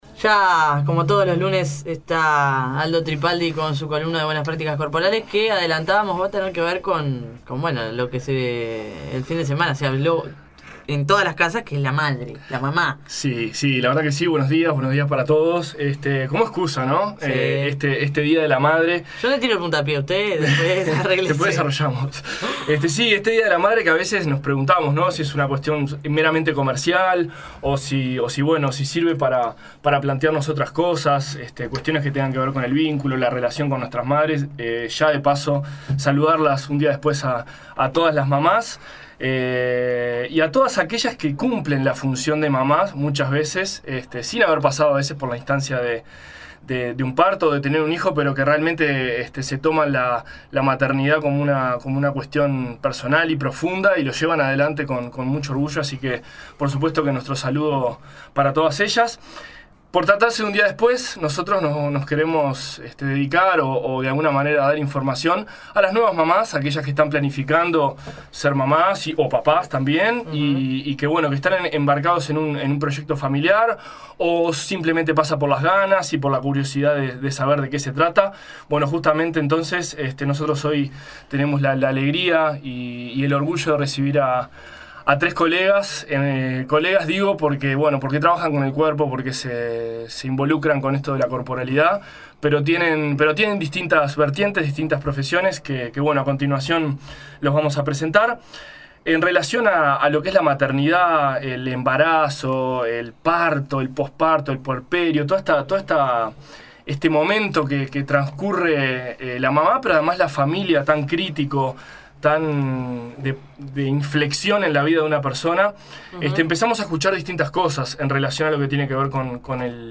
Conversamos sobre el proceso de embarazo con todos los cambios que esto conlleva, los físicos, emocionales y hasta los sociales; la preparación y el momento del parto, la lactancia y el nuevo vínculo que hace a una nueva familia son algunos de los temas que conversaremos en vivo con un excelente equipo multidisciplinario vinculado al colectivo “Nacer Mejor”.